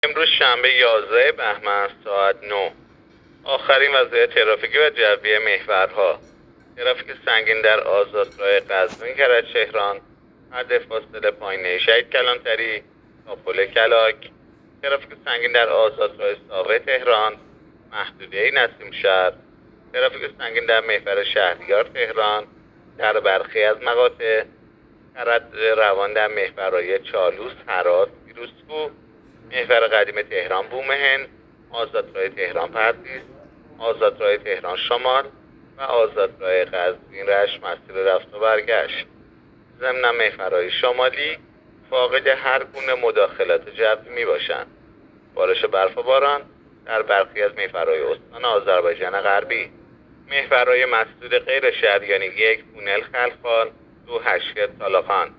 گزارش رادیو اینترنتی از آخرین وضعیت ترافیکی جاده‌ها ساعت ۹ یازدهم بهمن؛